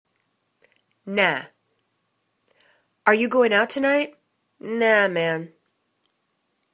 nah     /no/    adverb